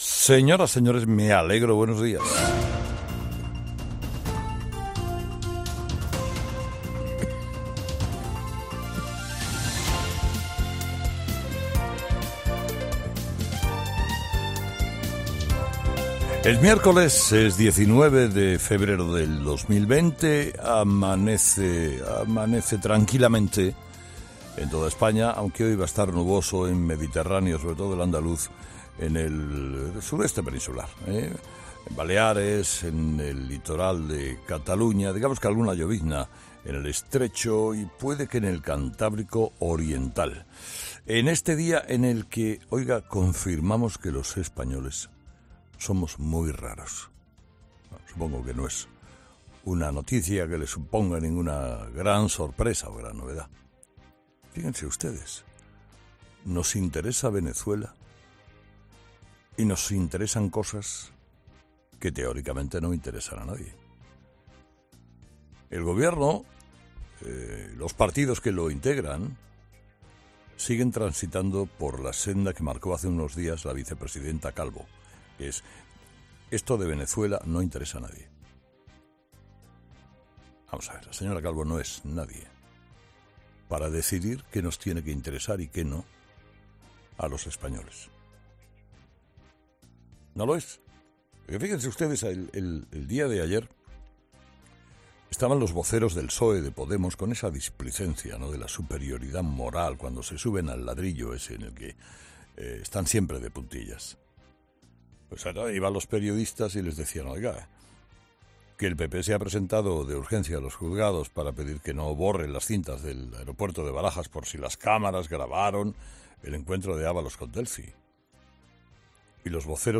Carlos Herrera analiza la decisión del juez que ve indicios de delito en el encuentro entre Ábalos y Delcy Rodríguez en Barajas